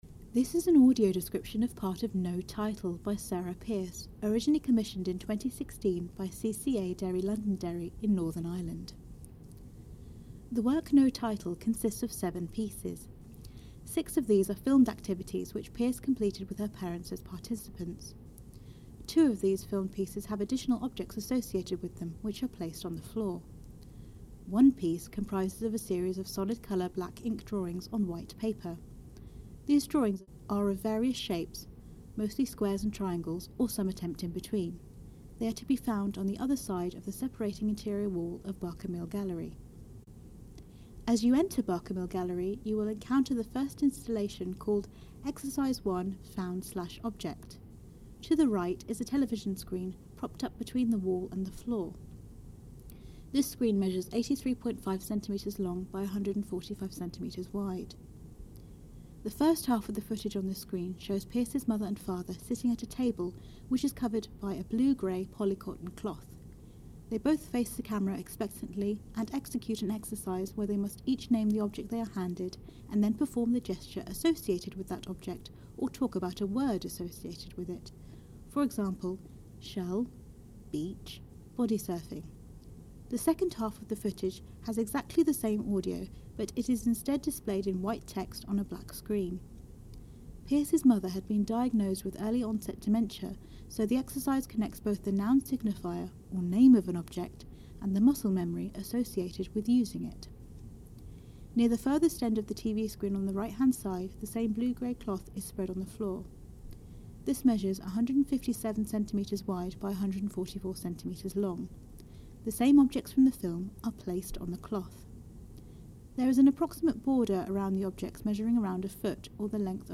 Audio Description of Sarah Pierce’s No Title
No-Title-Audio-Description.mp3